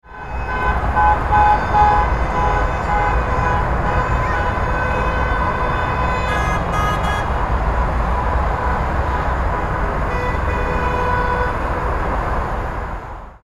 Car-driving-through-city-street-honking-sound-effect.mp3